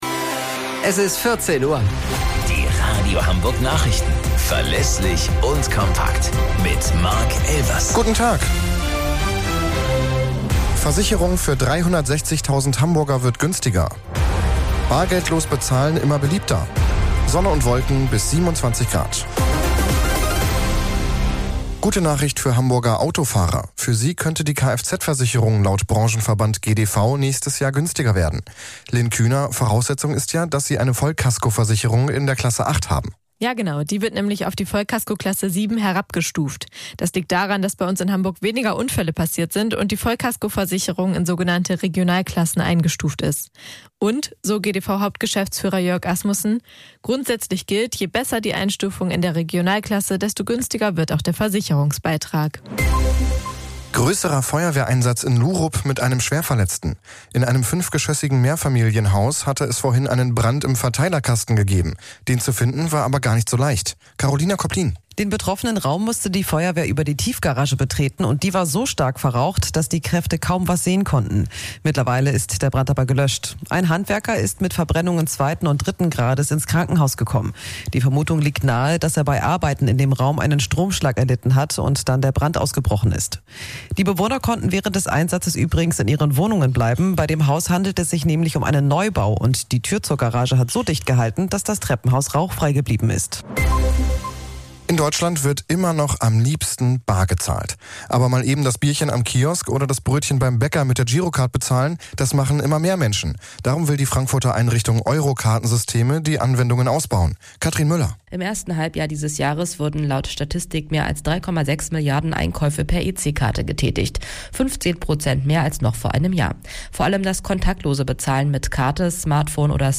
Radio Hamburg Nachrichten vom 27.10.2023 um 01 Uhr - 27.10.2023